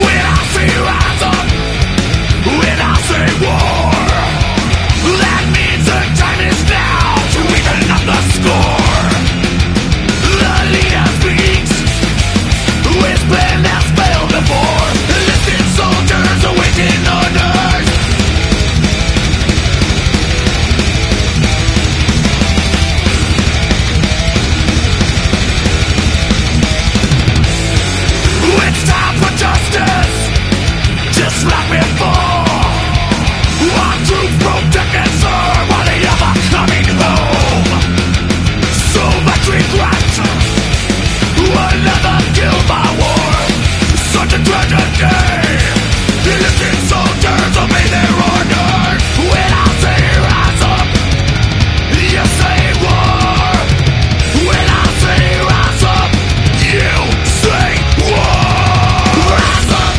buenos riffs, solos afilados, una atronadora base rítmica
Genre:Thrash metal